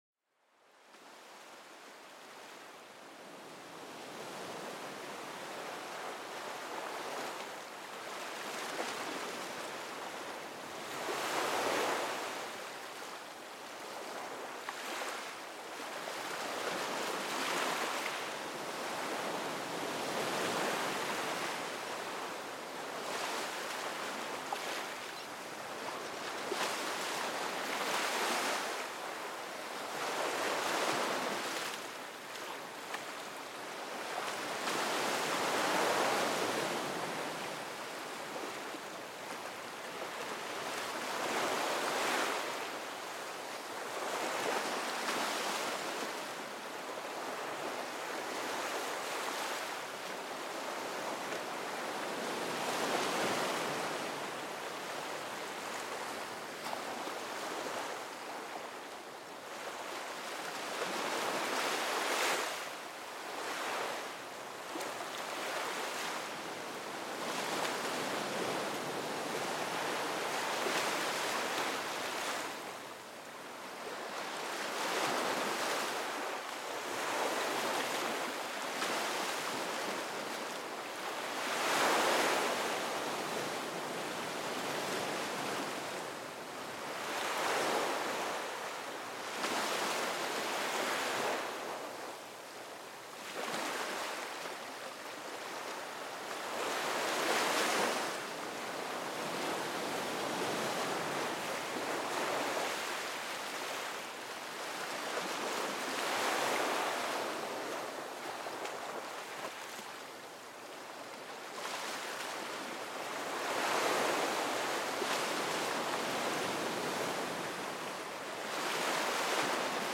Le murmure des vagues pour apaiser l'esprit